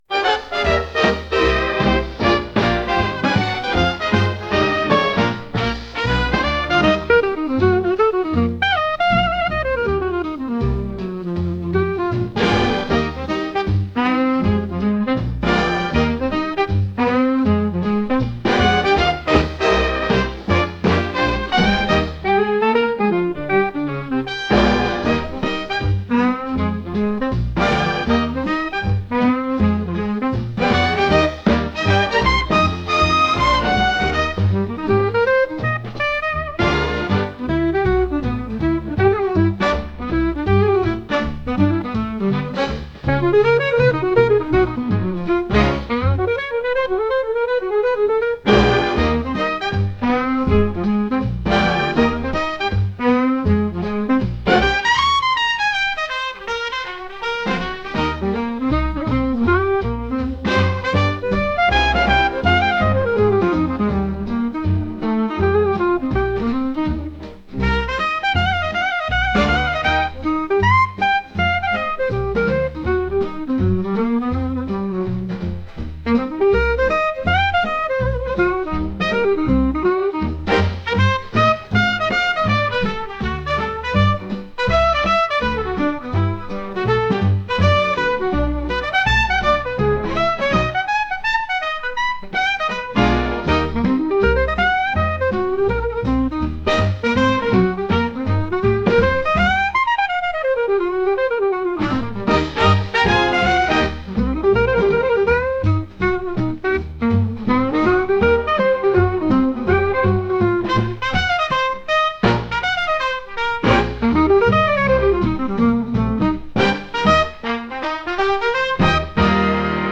オールドライプのスイングジャズ曲です。